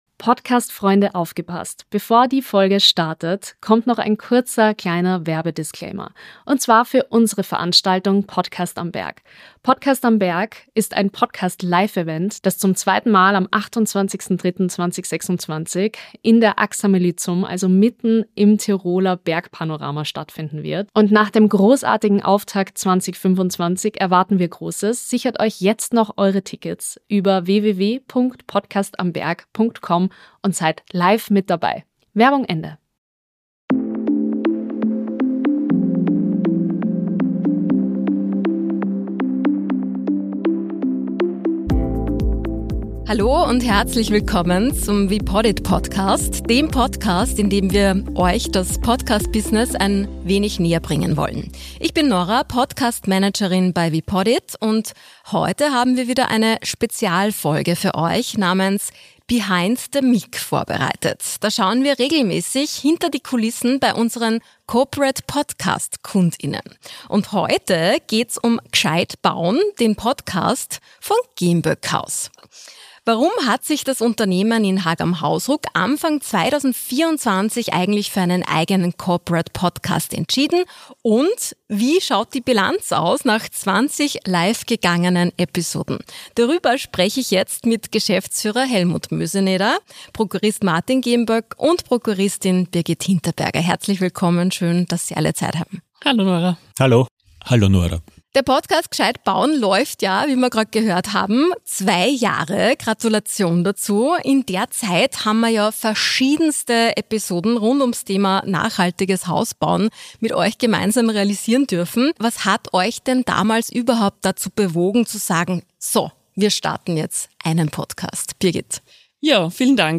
Im Rahmen unserer Interview-Reihe “Behind the mic” sprechen wir im wepodit Podcast mit Unternehmen darüber, warum sie sich für einen Podcast entschieden haben und welche Erfahrungen sie damit gemacht haben.